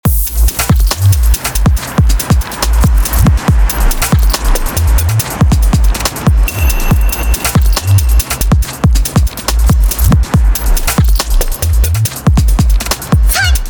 Color Limiterは、ハードウェアのリミッターに特有のザラついたサウンドから着想を得ています。
●Color Limiter+10db
まったく歪まない代わりにめちゃくちゃポンピングしてます。